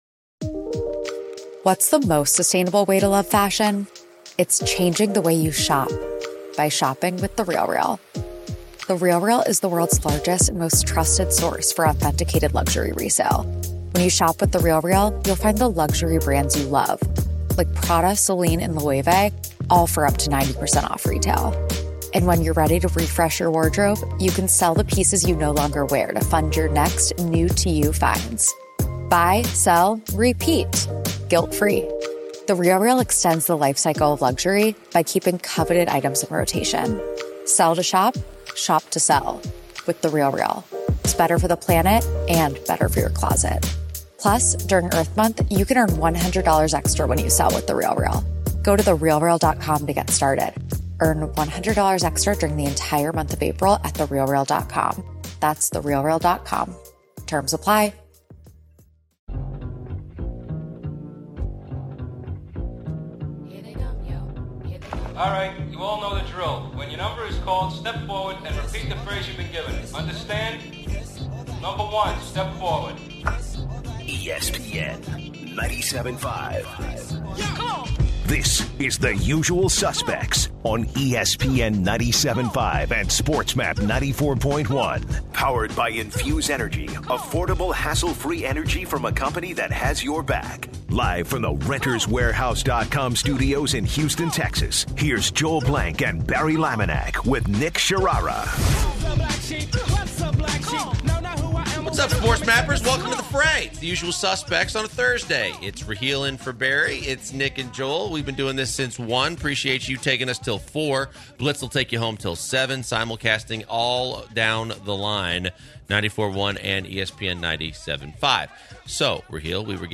The guys finish up the show with callers giving their take on the Art Briles situation.